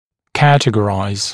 [‘kætəgəraɪz][‘кэтэгэрайз]классифицировать, категоризировать, распределять по категориям